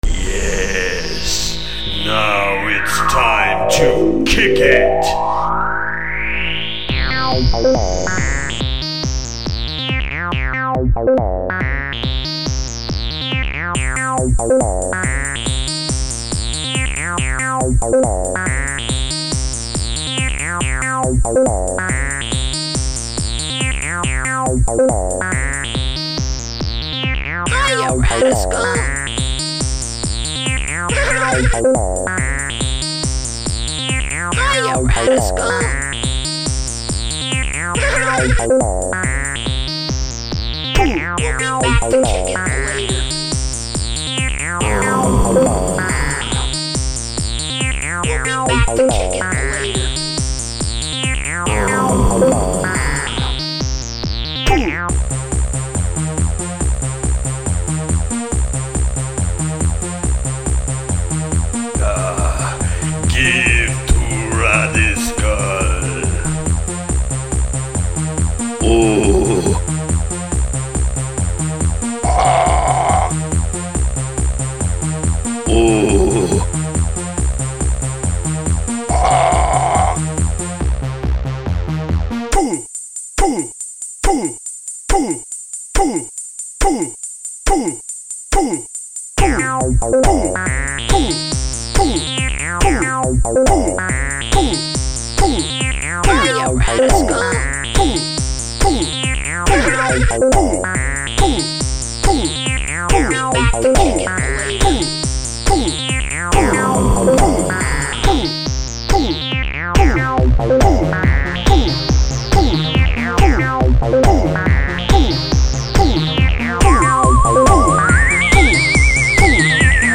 catchy techno dance track